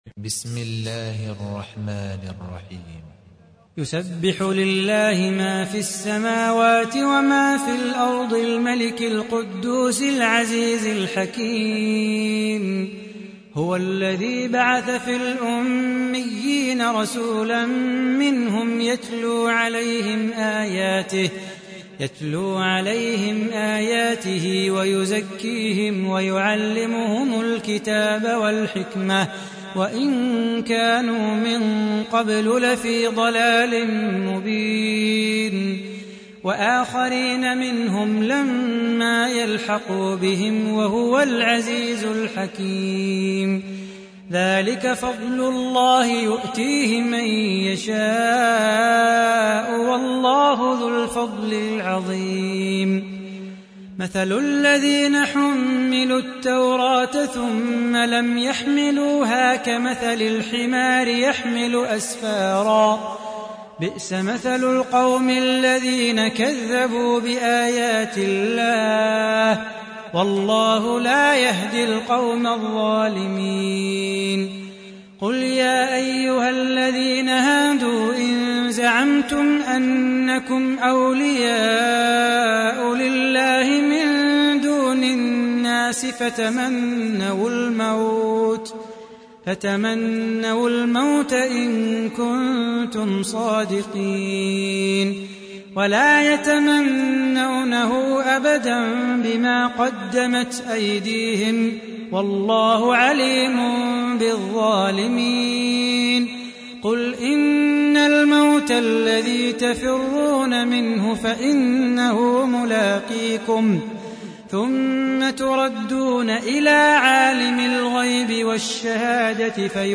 تحميل : 62. سورة الجمعة / القارئ صلاح بو خاطر / القرآن الكريم / موقع يا حسين